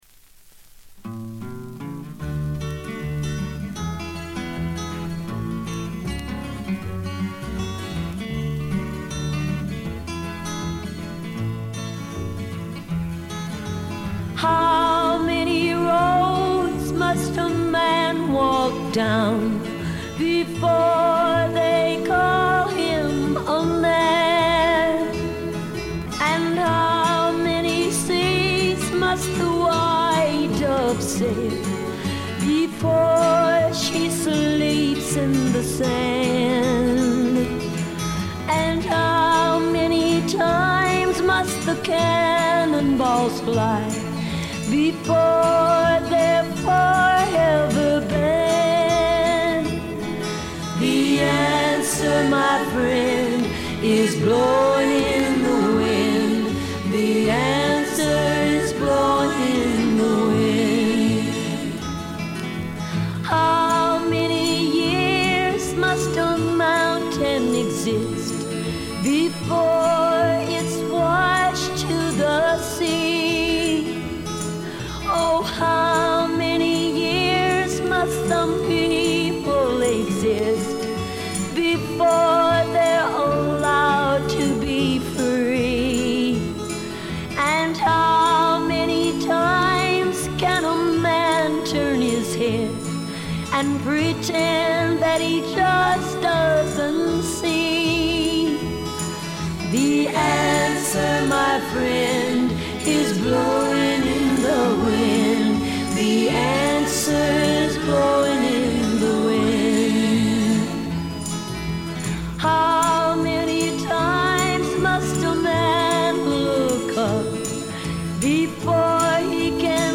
存在感ありまくりのヴォーカルが素晴らしいです。
試聴曲は現品からの取り込み音源です。